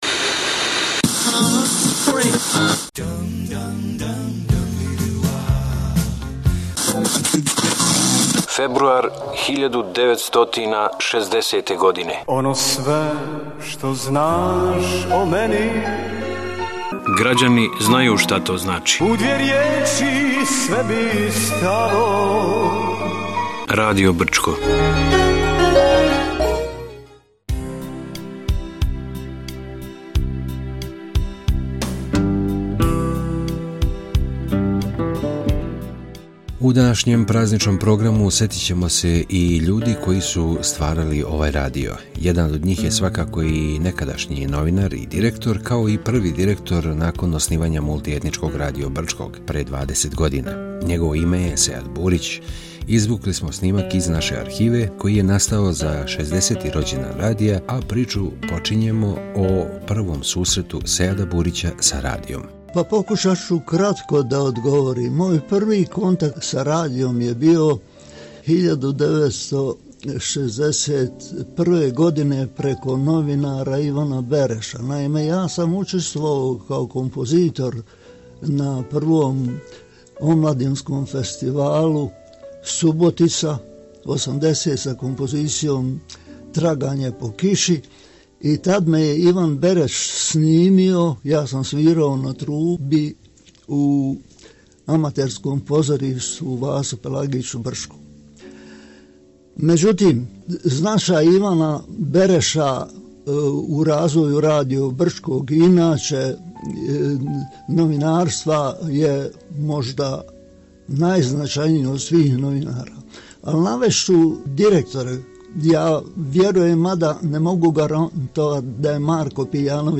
Емисија је снимљена поводом 60. годишњице од оснивања радија.